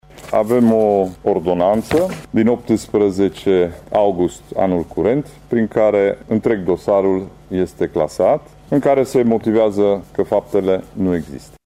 Faptele pentru care a fost cercetat în ultimii 4 ani și jumătate avocatul tîrgumureșean Ciprian Dobre, nu au existat, iar dosarul a fost clasat, a anunțat acesta luni, în cadrul unei conferințe de presă.